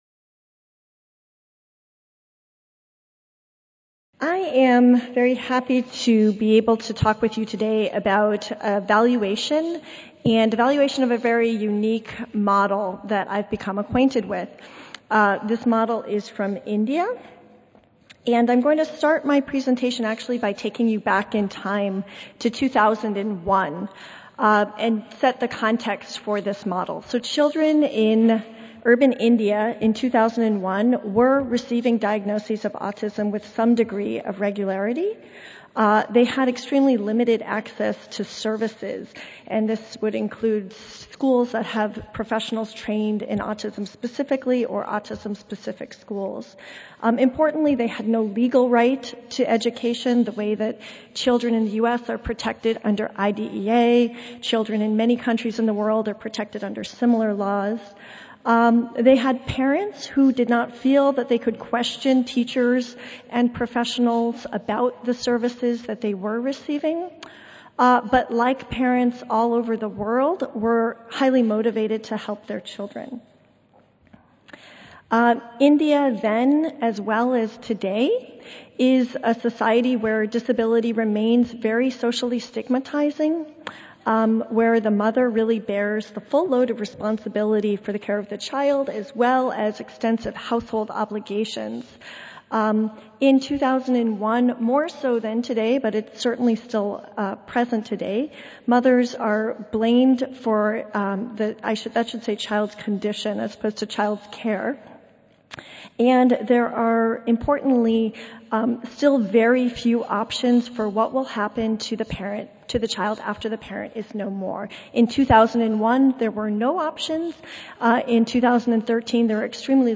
Auditorium (Kursaal Centre)
Recorded Presentation